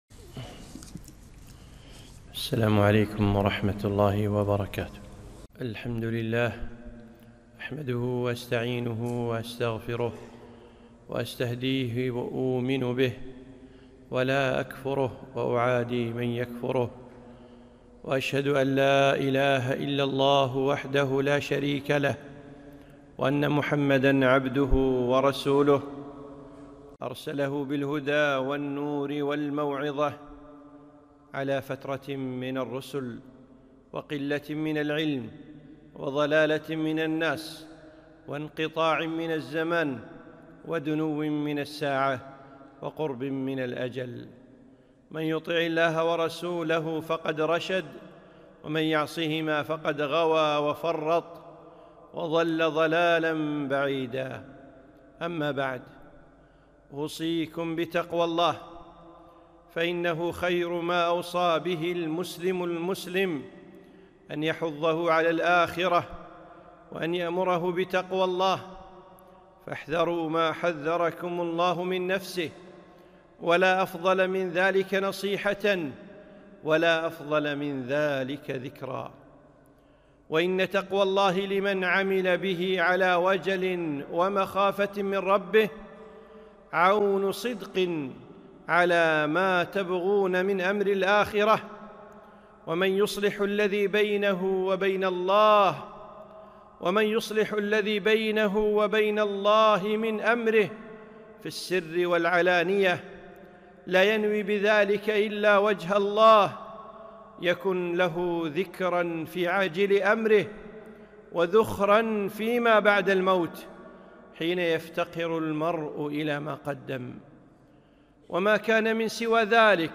خطبة - أول الخطب